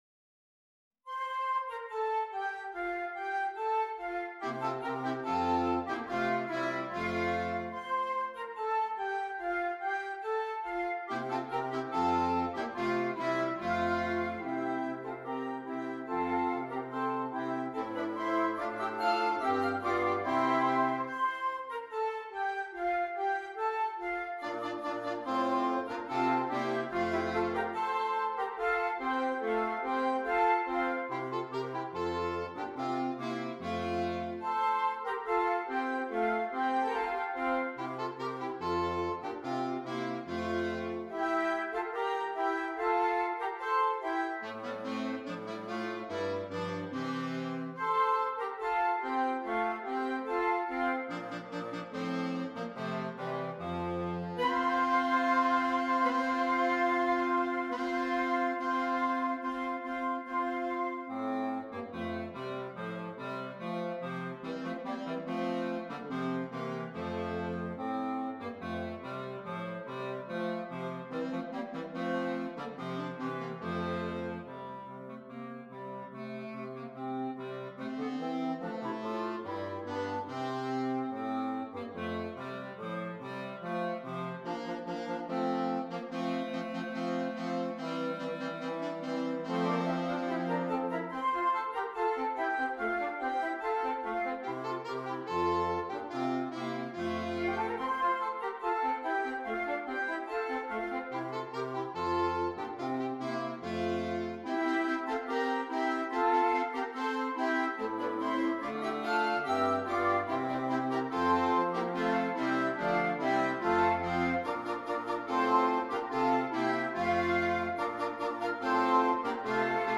Interchangeable Woodwind Ensemble
Traditional Carol